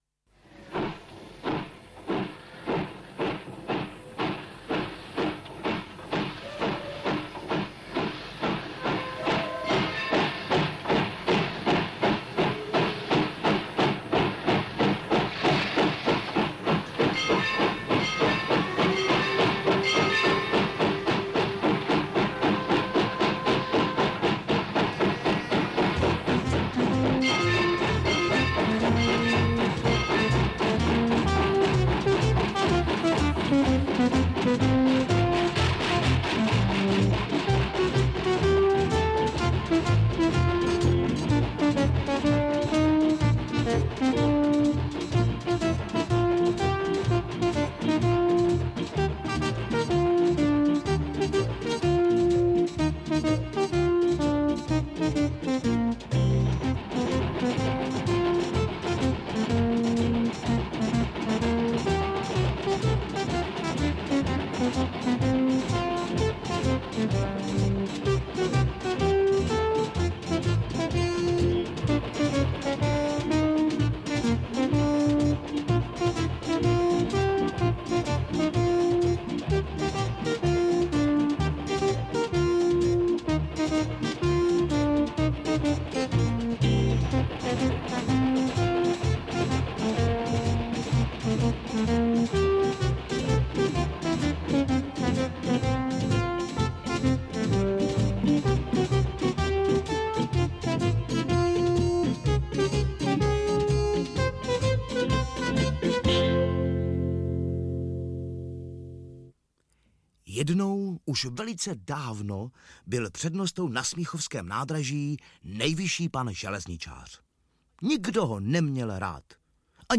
Interpret:  Pavel Zedníček
AudioKniha ke stažení, 2 x mp3, délka 1 hod. 29 min., velikost 81,2 MB, česky